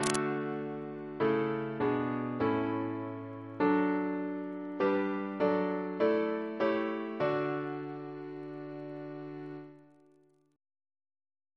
Single chant in D Composer: Richard Tomlinson (b.1822) Reference psalters: OCB: 171